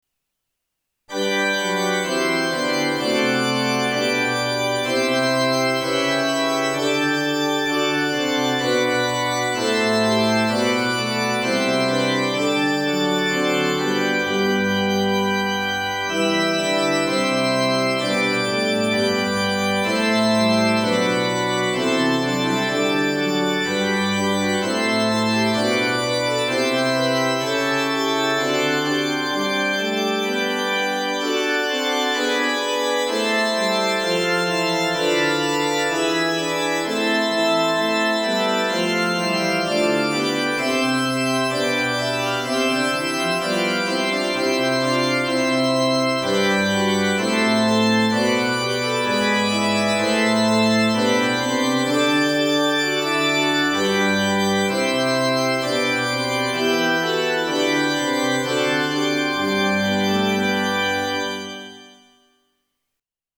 Tonality：G (♯)　Tempo：Quarter note = 64
1　 Organ